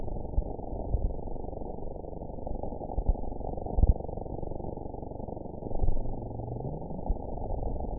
event 922712 date 03/17/25 time 20:04:24 GMT (1 month, 2 weeks ago) score 9.13 location TSS-AB03 detected by nrw target species NRW annotations +NRW Spectrogram: Frequency (kHz) vs. Time (s) audio not available .wav